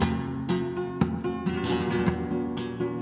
GUITAR LOOPS - PAGE 1 2 3 4
FLAMENCO 2 (261Kb)
Flamenco2.wav